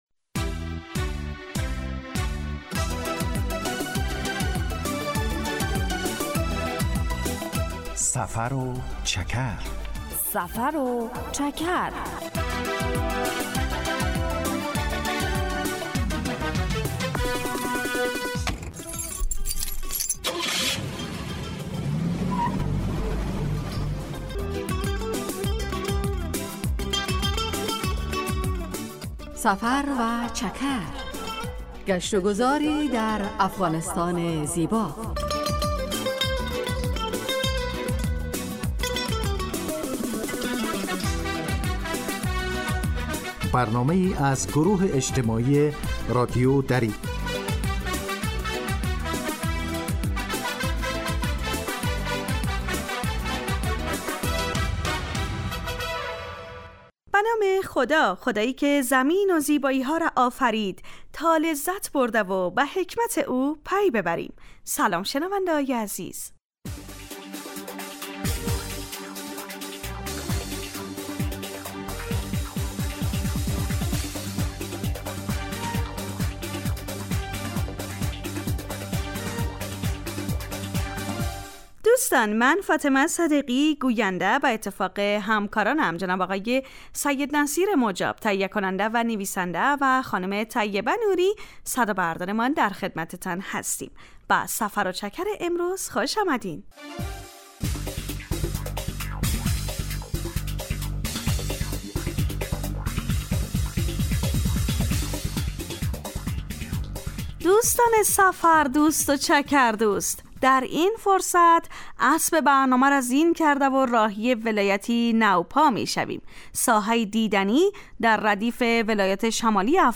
در سفر و چکر ؛ علاوه بر معلومات مفید، گزارش و گفتگو های جالب و آهنگ های متناسب هم تقدیم می شود.